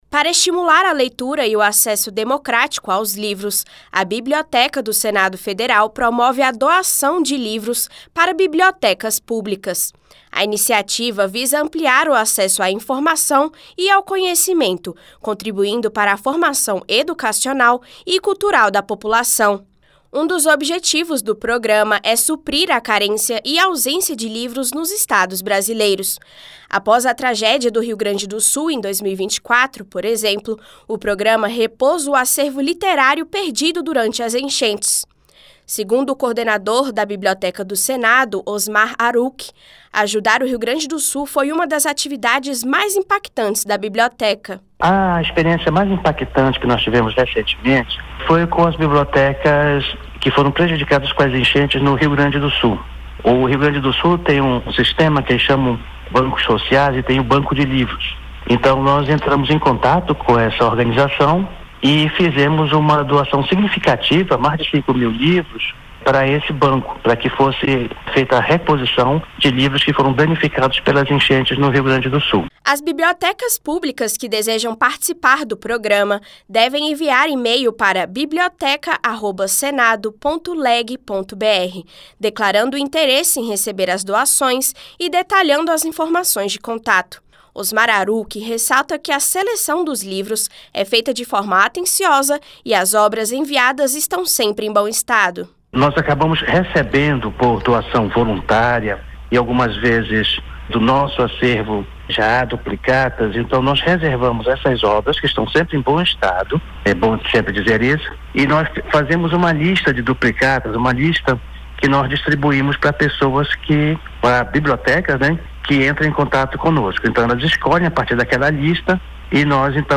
Rádio Senado : Notícias